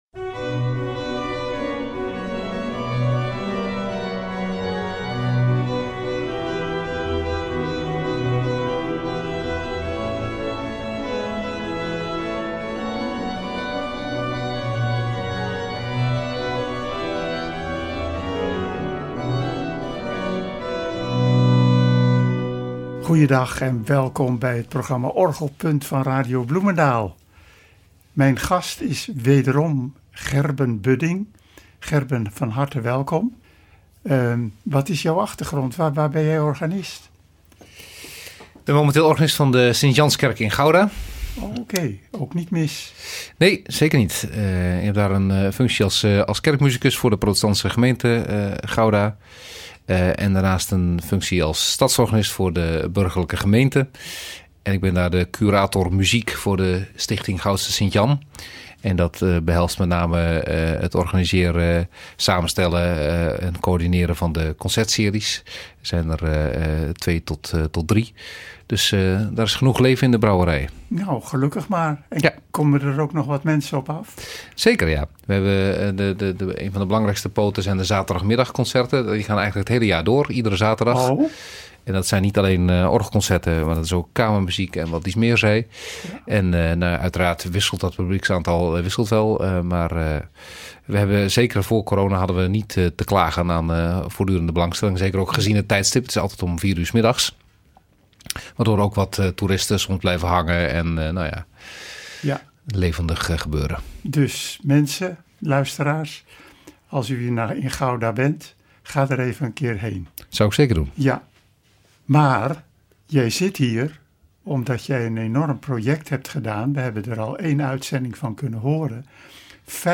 Met genres als Sonate Divertimenti en psalmvariaties.